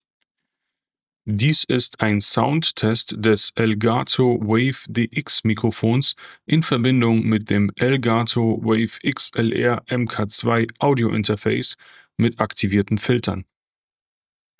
Soundtest
Softwareseitig ist die Lautstärke auf 60 % eingestellt und die Aufnahmedistanz beträgt etwa 20 Zentimeter.
Test 4: Elgato WAVE XLR MK.2 mit relevanten Filtern